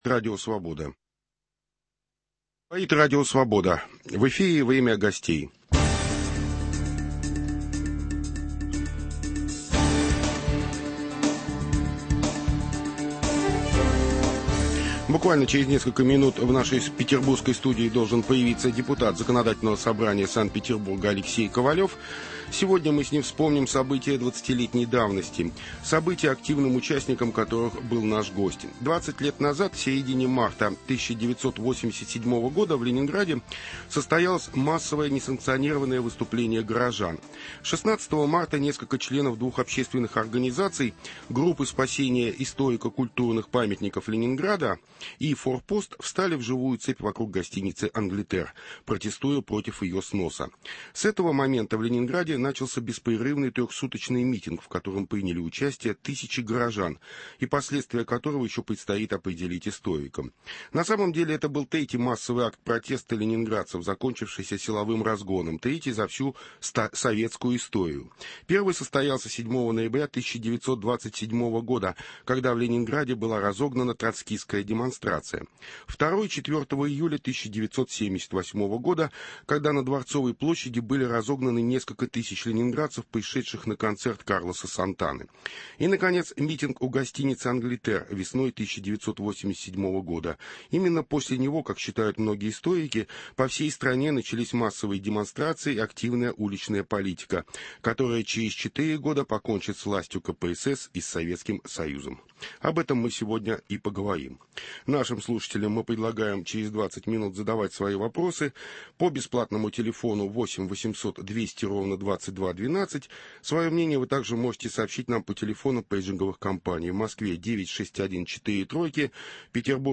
Вспоминают и анализируют очевидцы и участники тех событий